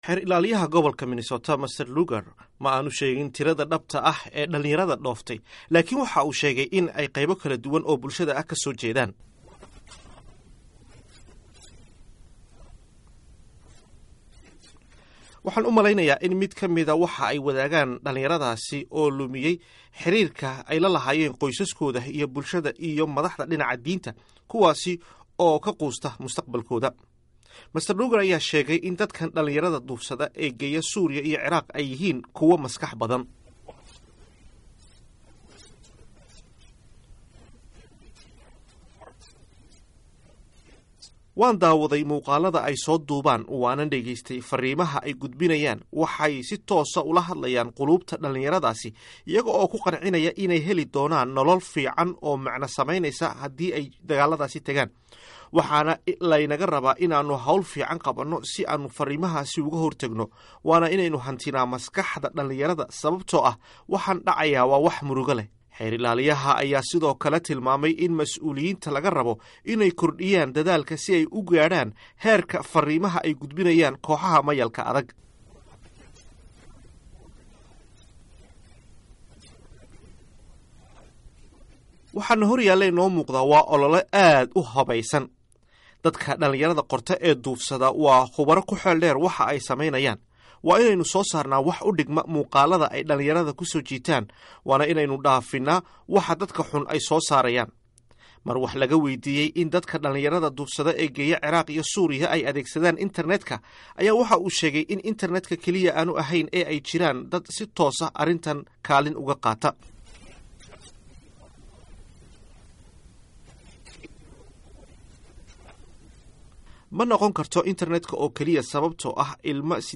Waraysi Gaar ah: Xeer Ilaaliyaha Minnesota
Dhegayso: waraysi gaar ah oo VOA la yeelatay Xeer ilaaliyaha Minnesota